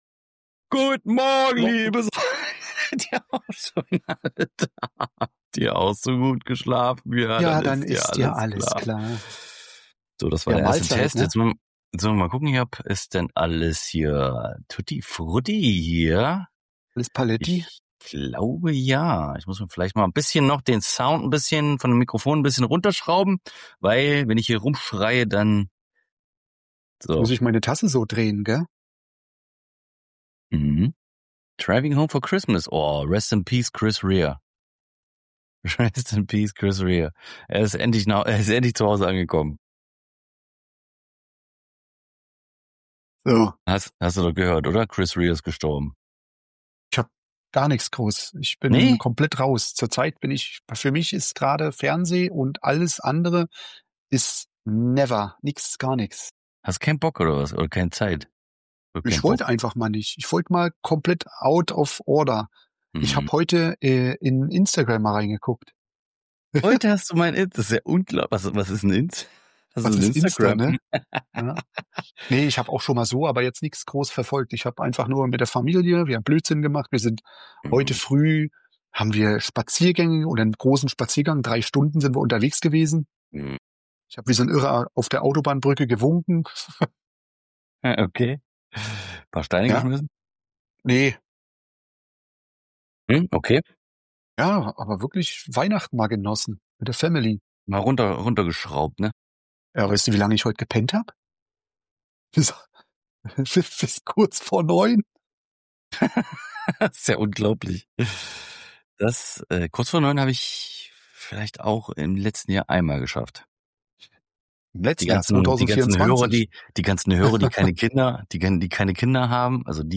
Sie reflektieren über Umweltprobleme und innovative Lösungen, während sie auch rechtliche Aspekte des Mietrechts ansprechen. Die Unterhaltung ist geprägt von Humor und persönlichen Anekdoten.